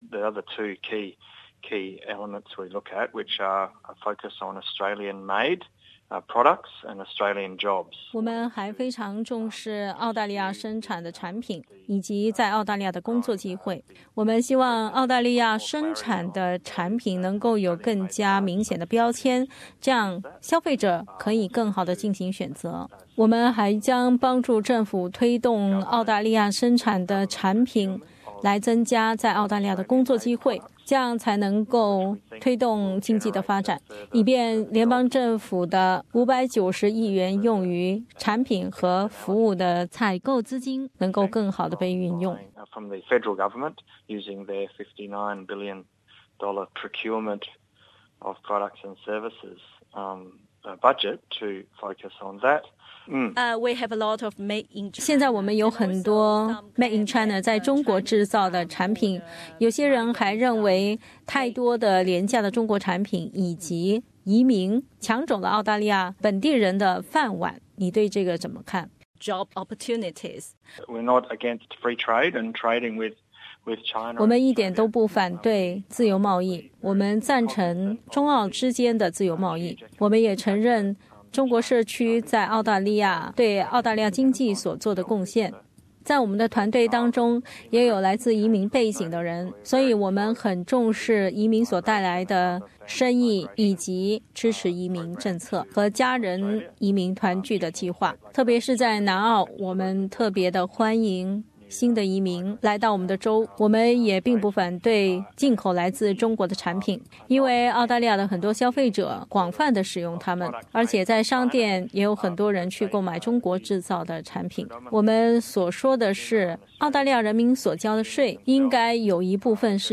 他是收到了SBS普通话节目华人谈大选的总结报告后，众多给我们节目组回复的其中一位候选人，并接受了本台记者的采访。